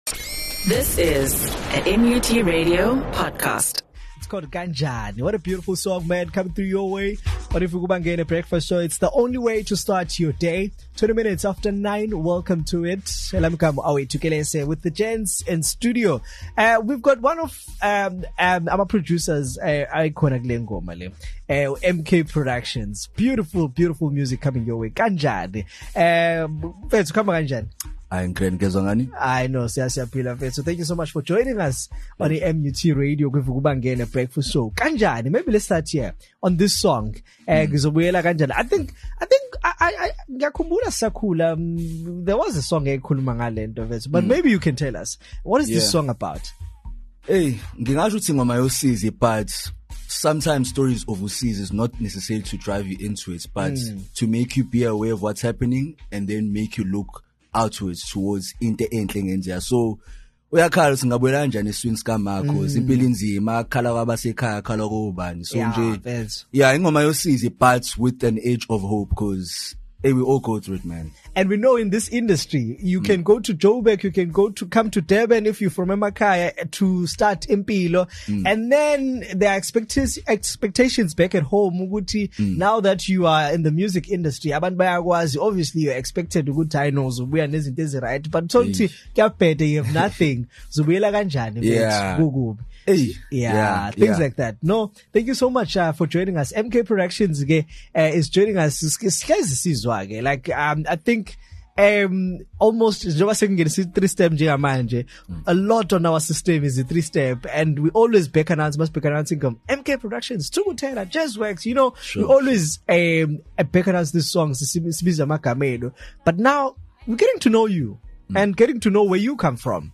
breakfast show